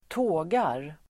Uttal: [²t'å:gar]